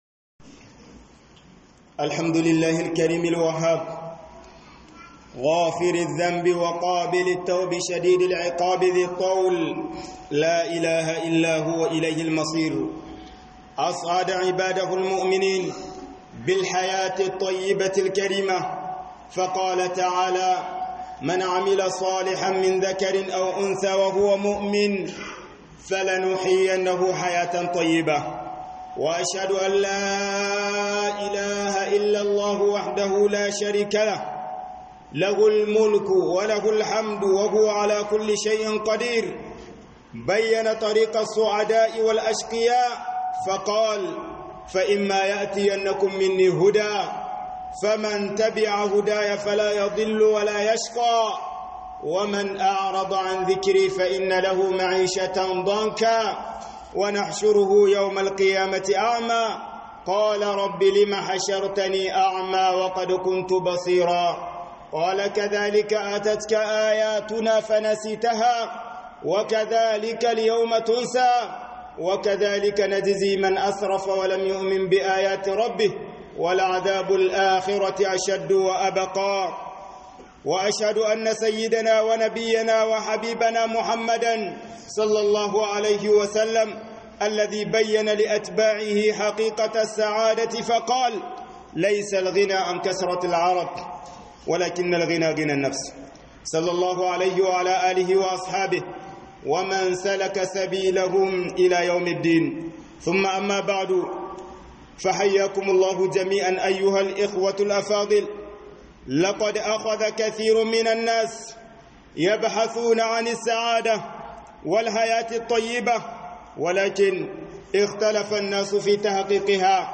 ARZIKI BA SHINE JINDADI RAYIWA BA - Huduba